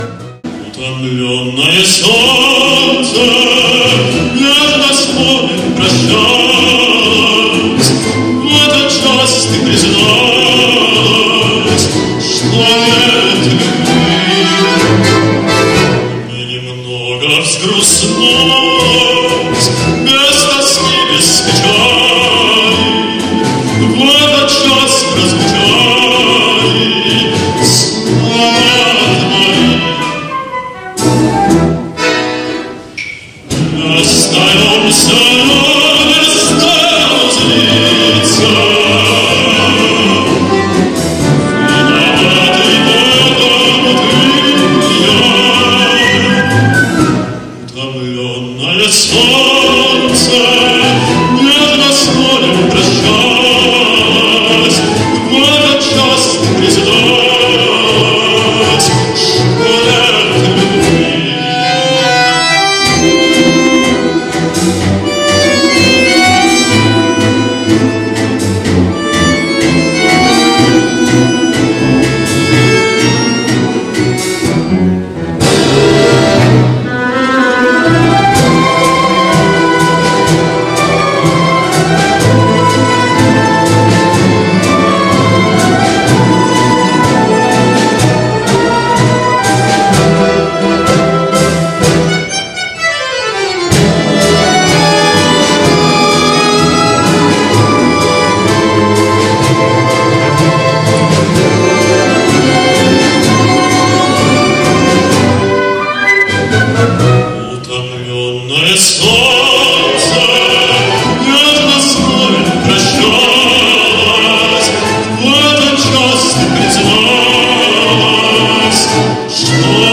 На ролике YouTube танец в сопровождении голоса.